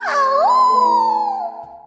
awooo.wav